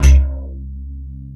BACKBASSC2-L.wav